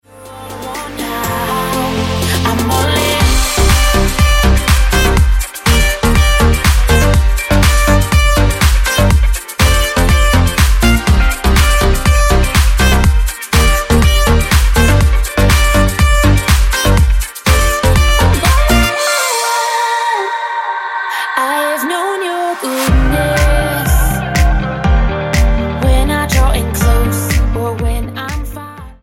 Irish worship team
Style: Pop Approach: Praise & Worship